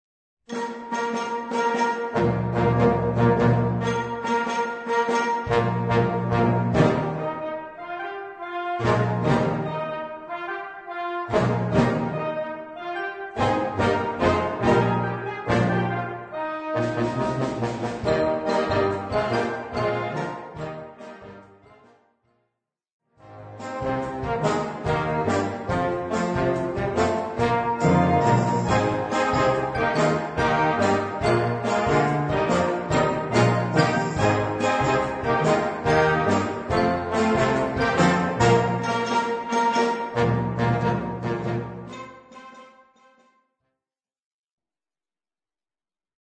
Gattung: 4 Part-Ensemble
Besetzung: Blasorchester